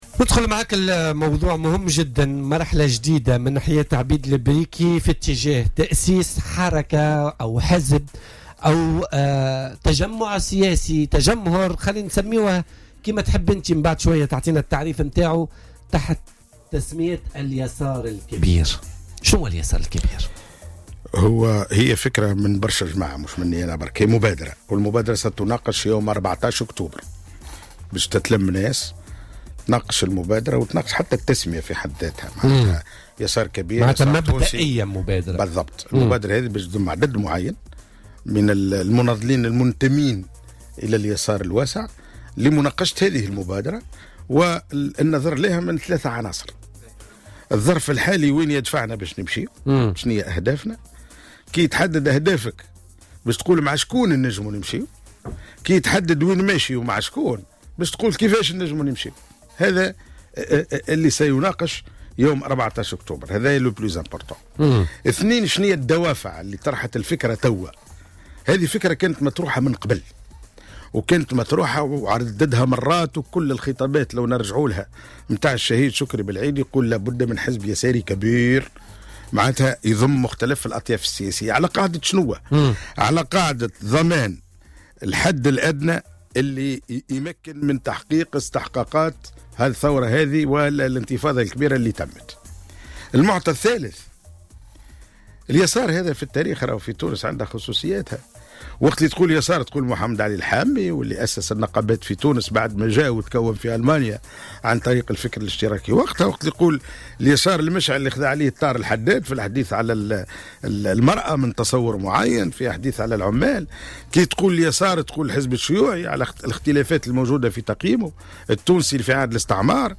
قال الوزير السابق والنقابي عبيد البريكي، خلال استضافته اليوم في "بوليتيكا" أنه سيعلن يوم 14 أكتوبر 2017، عن مبادرة "اليسار الكبير" وسيصدر بيانه التأسيسي ثم الإعلان عن هيئته لاحقا.